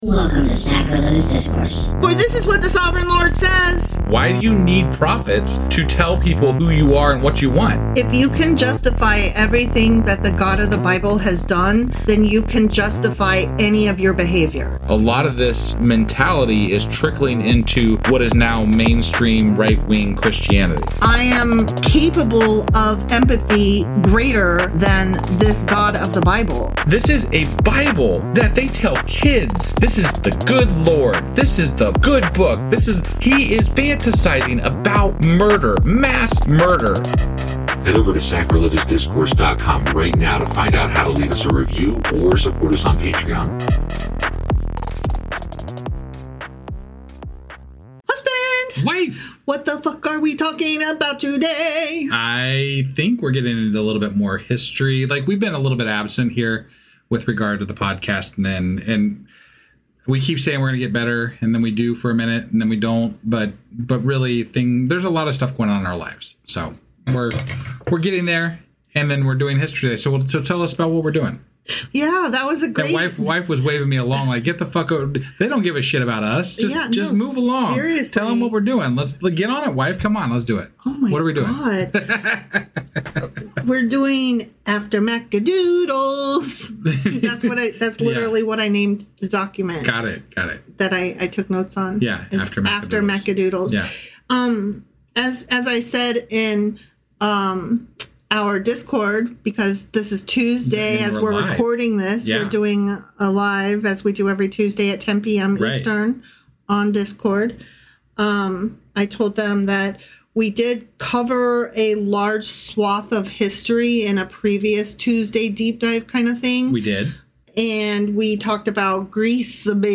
Bible Study by Atheists For Anyone!
It’s a snarky, history-heavy takedown of the myth that nothing happened between the testaments. The hosts dig into the rise of the Pharisees, Sadducees, Essenes, and Zealots , the political theater of Herod the Great , and how messianic obsession, apocalyptic thinking, angels, demons, resurrection talk, and “end times” vibes were already bubbling long before Christianity slapped its logo on the franchise.